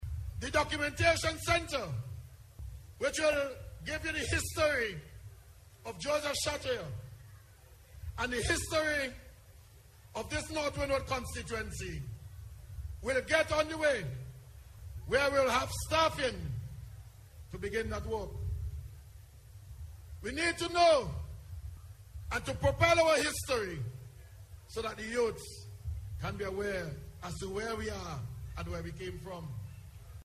Minister Daniel, who is also the Parliamentary Representative for North Windward, was speaking at the Unity Labour Party’s 24th Anniversary Celebration Rally recently.